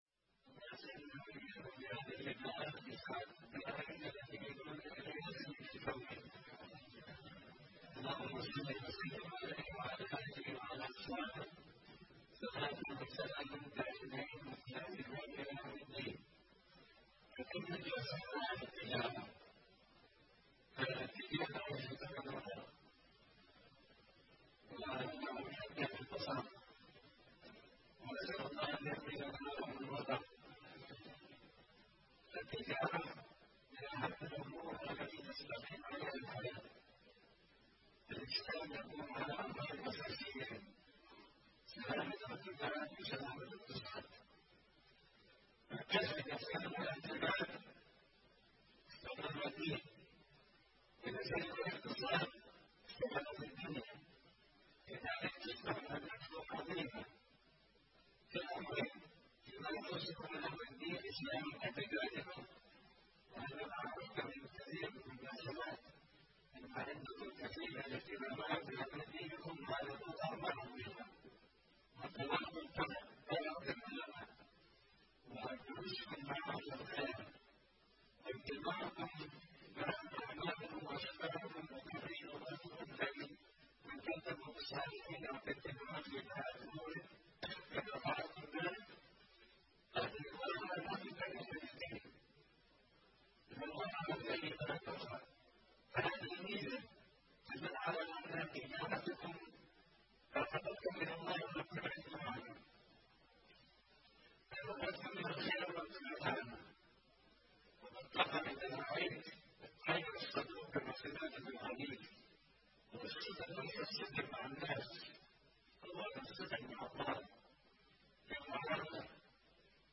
كلمة
في الملتقى السنوي لأصحاب المحلات التجارية والعاملين فيها بسوق تريم